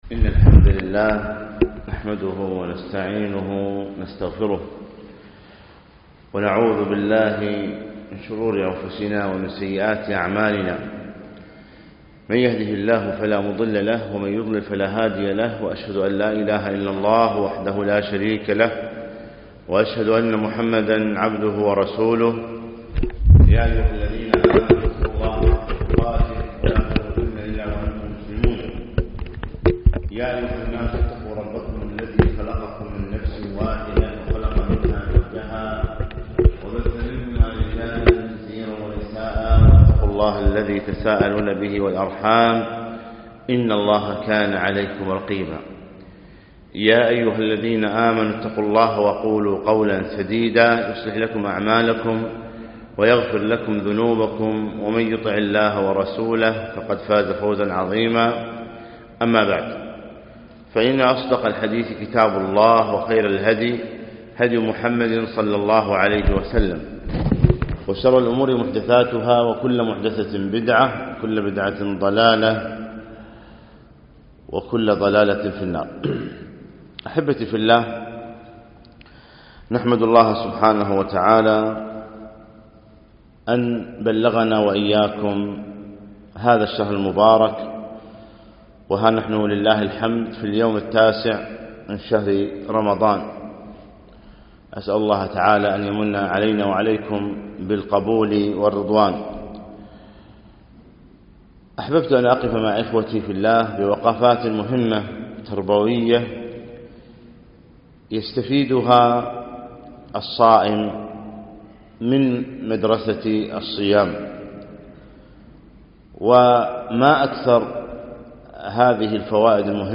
يوم الثلاثاء 9 رمضان في مسجد سعد بن عبادة منطقة خيطان